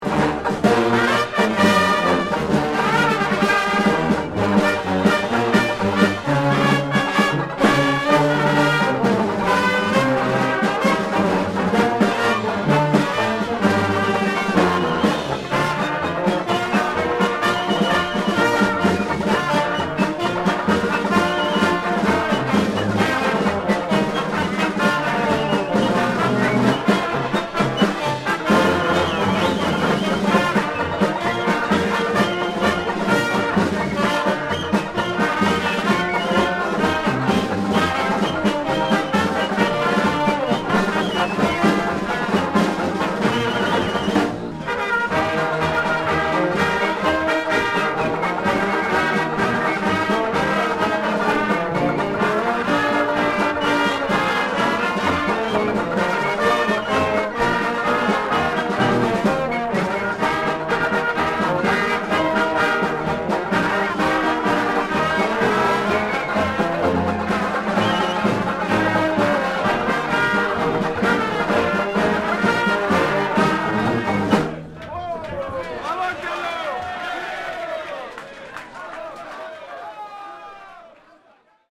Marches et galops